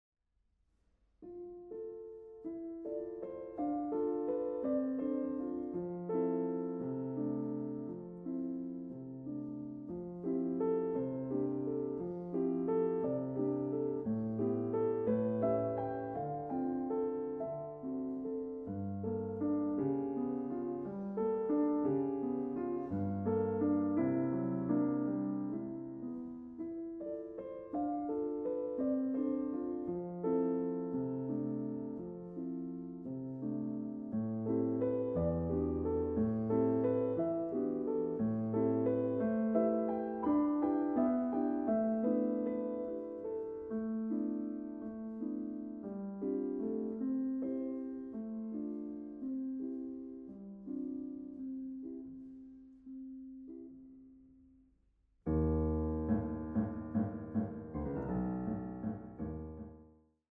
A piano journey through various European musical worlds
Piano